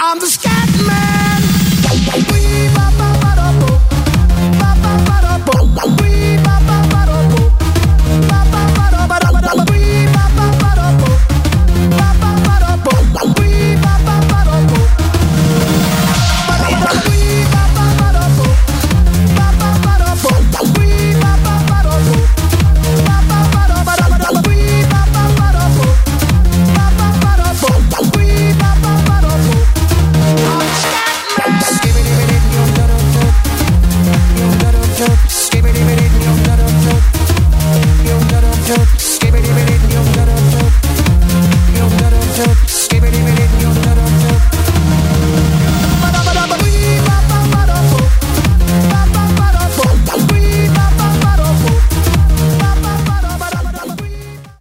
• Качество: 320, Stereo
диско
electro